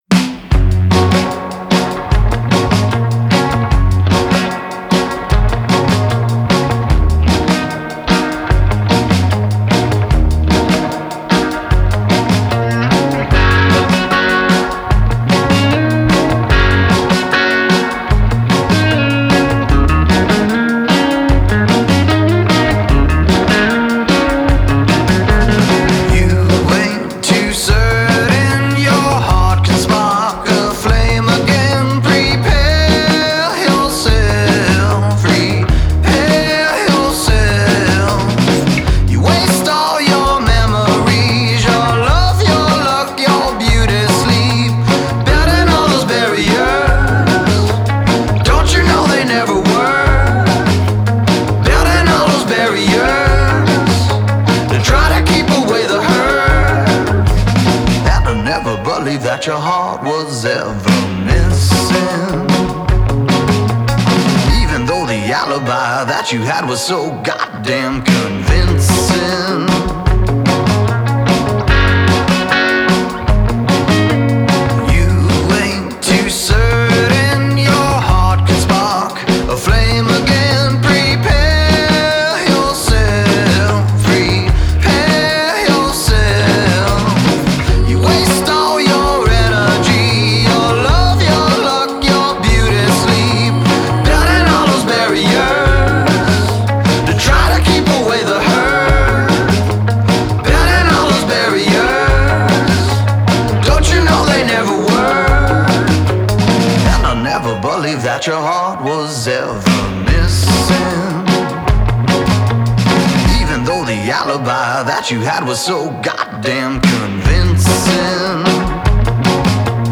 has surf style guitar work